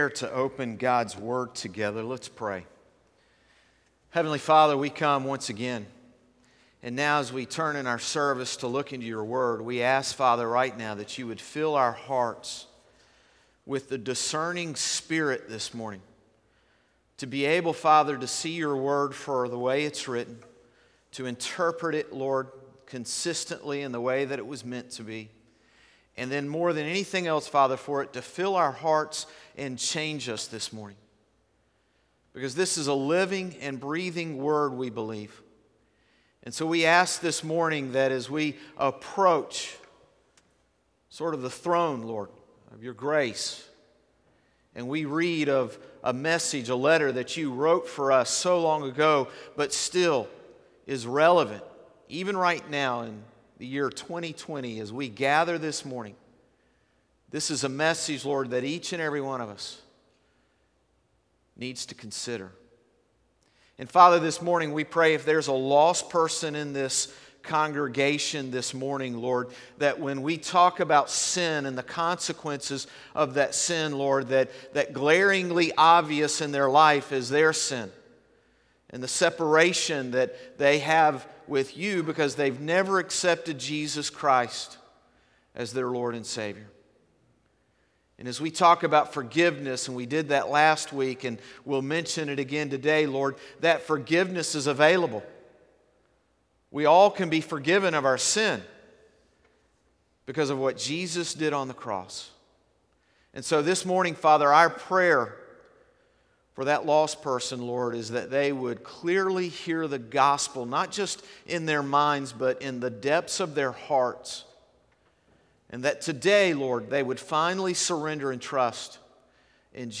Morning Service - Consequences of Our Sins | Concord Baptist Church
Sermons - Concord Baptist Church